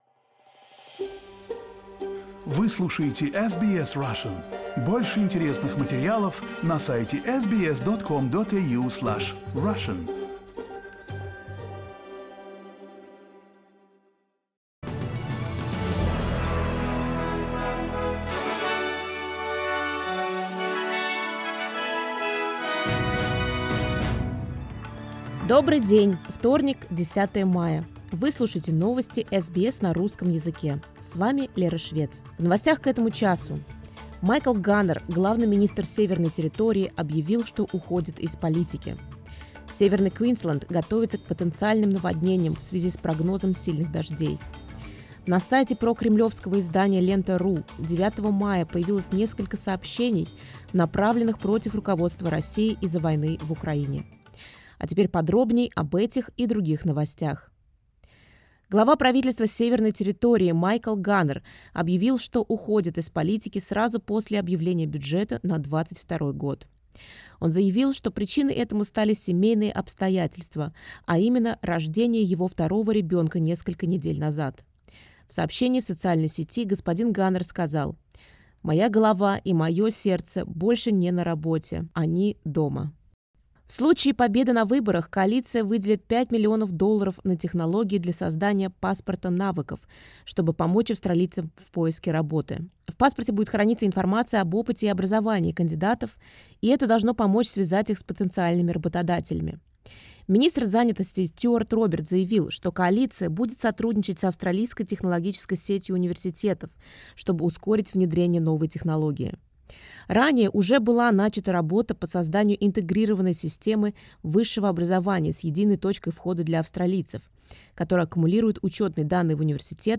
SBS news in Russian — 10.05.22